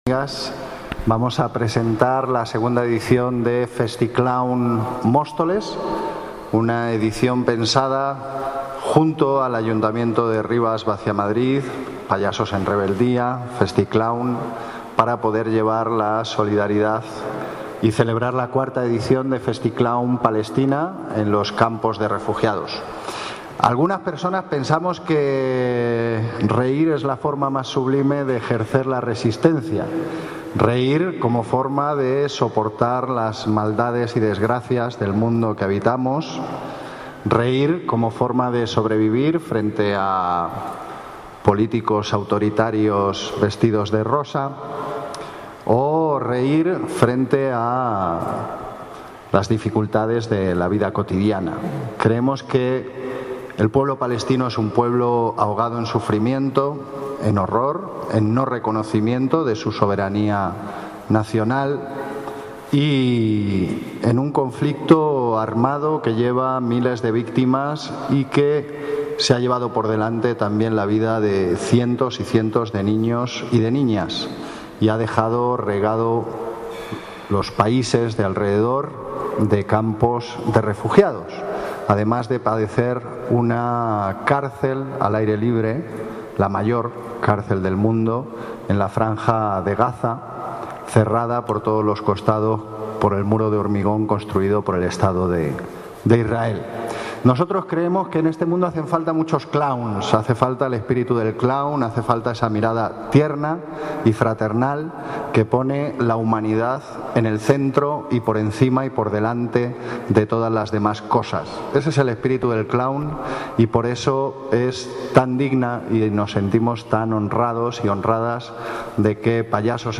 Audio - Gabriel Ortega (Concejal de Cultura, Bienestar Social y Vivienda) Presentación de Festiclown